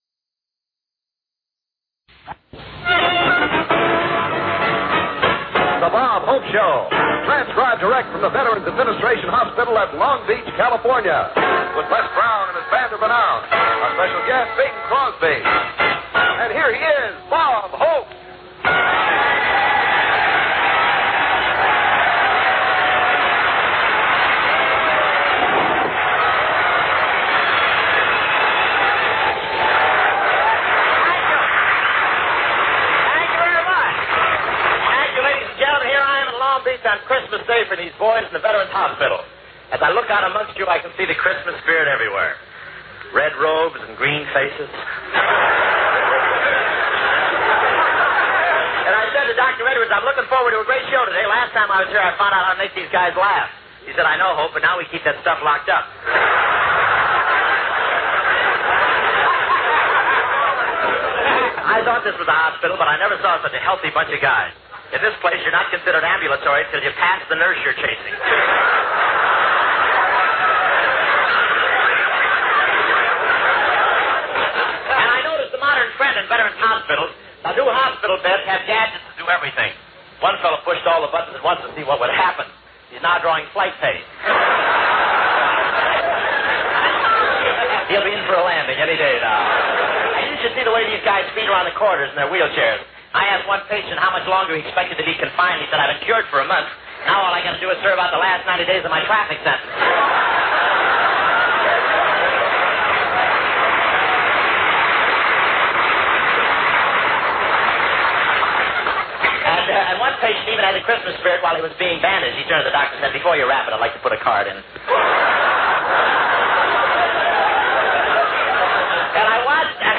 OTR Christmas Shows - Christmas Show From VA Hospital Long Beach, California - 1945-12-18 AFRS The Bob Hope Show
OTR Radio Christmas Shows Comedy - Drama - Variety.